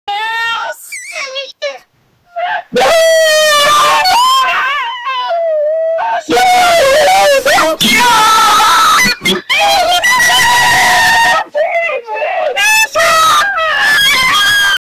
peoople having fun and yelling at a party
peoople-having-fun-and-ye-35iwquxy.wav